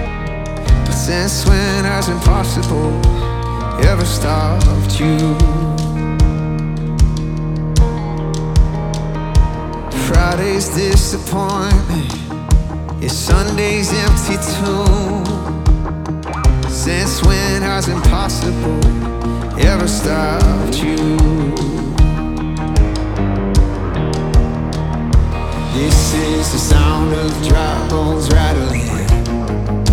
Genre: Christian & Gospel